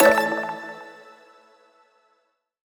shutter.mp3